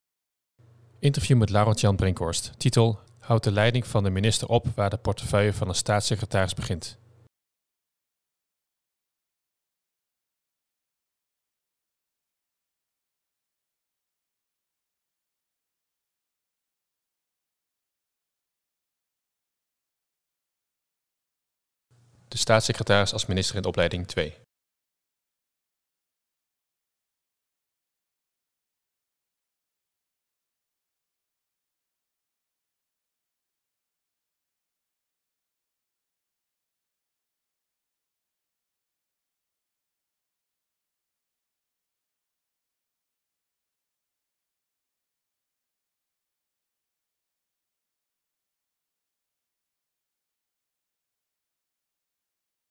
Interview met Laurens Jan Brinkhorst.